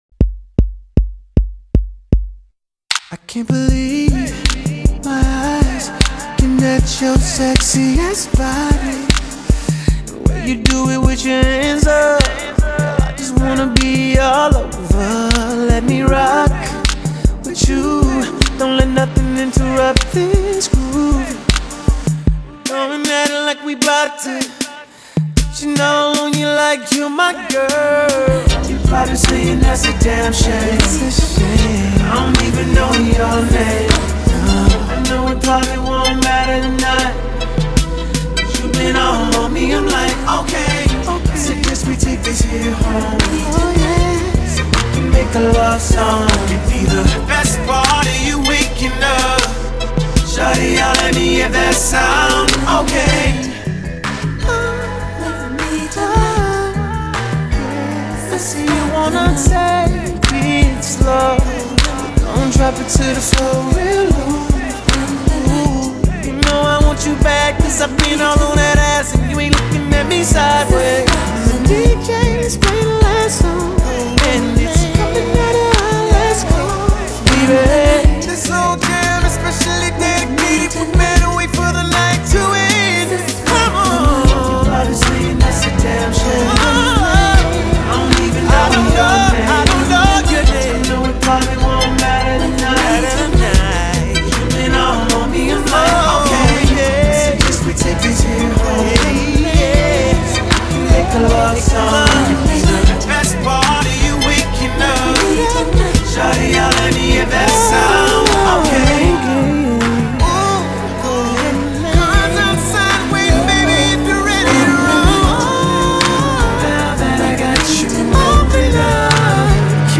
Old School RnB
Description: something to ride to